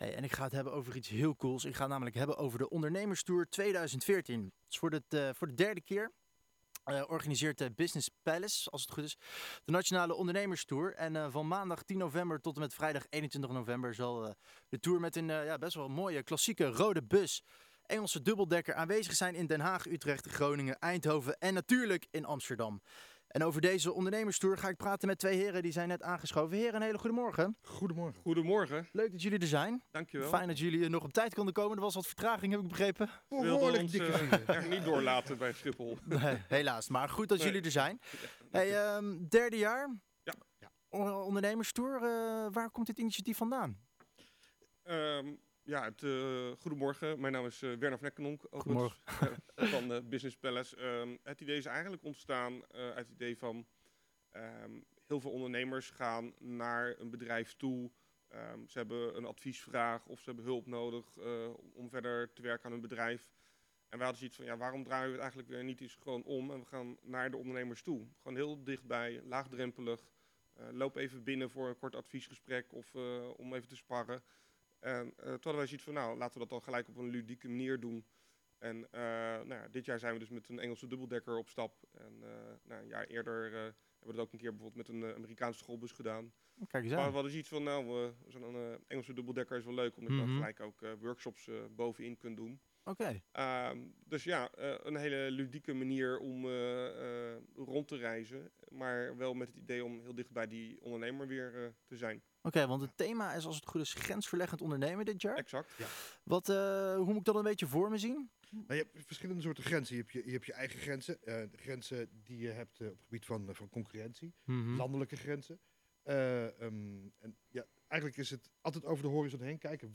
Wij spraken twee van de initiatiefnemers in AmsterdamLight.